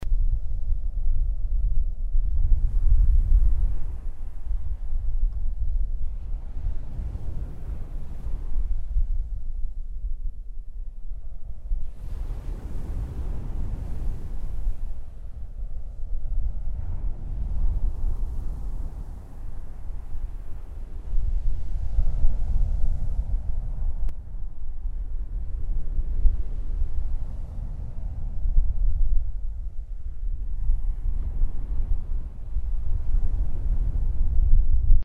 Over the past few months I've started to dabble in nature audio recordings.
South Beach, Martha's Vineyard, June, 2004
south beach short2.mp3